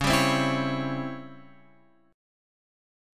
C#mM7b5 Chord